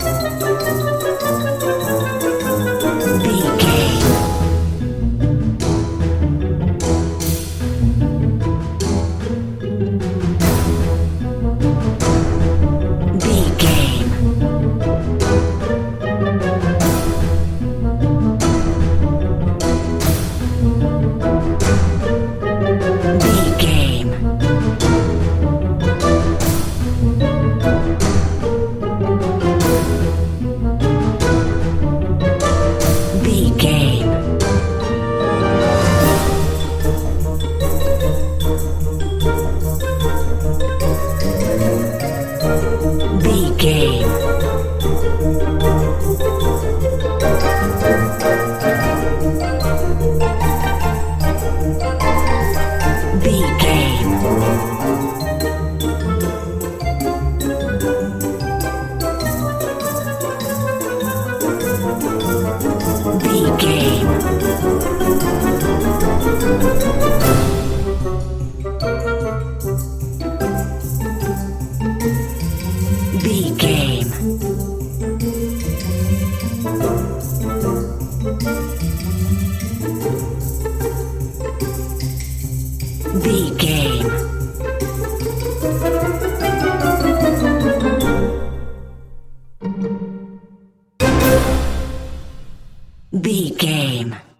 Uplifting
Lydian
flute
oboe
strings
orchestra
cello
double bass
percussion
silly
circus
goofy
comical
cheerful
perky
Light hearted
quirky